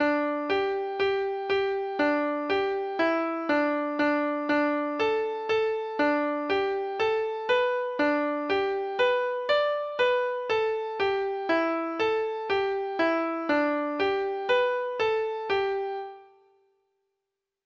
Folk Songs
Traditional Music of unknown author.